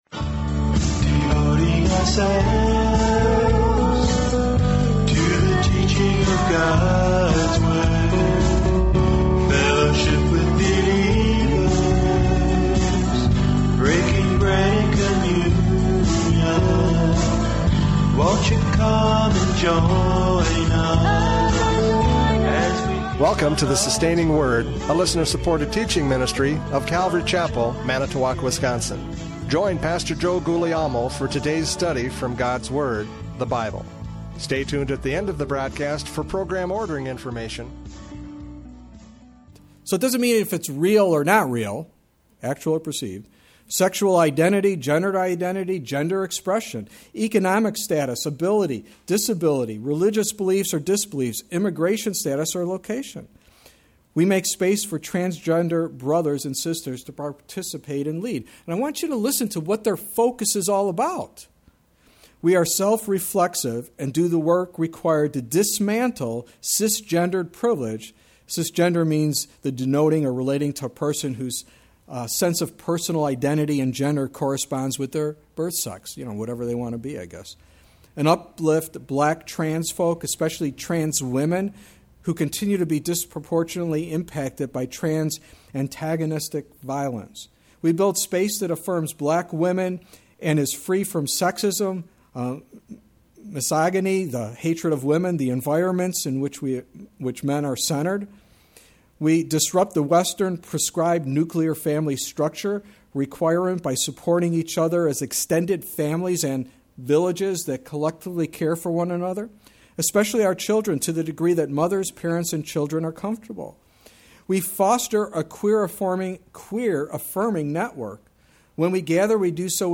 Judges 11:1-11 Service Type: Radio Programs « Judges 11:1-11 Love Shines Through!